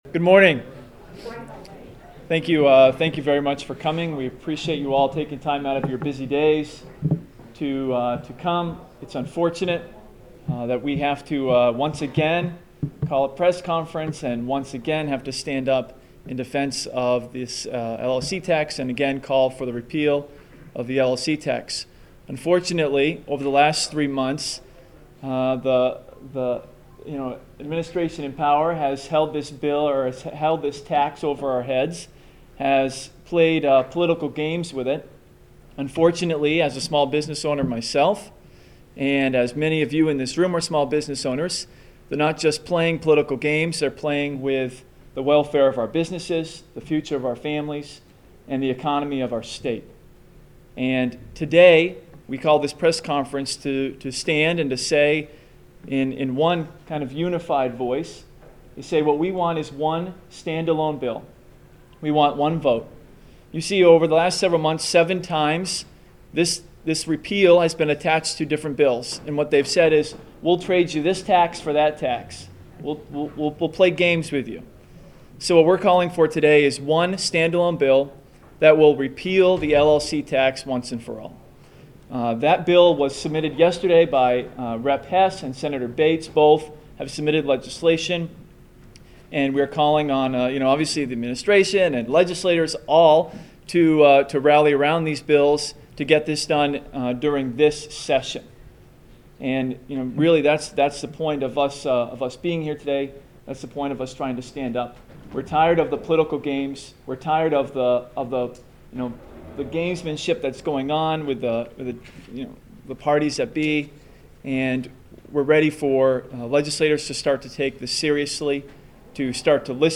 The following audio cuts are soundbytes from the 6/8/10 LLC Tax press conference at the State House and aired on the show: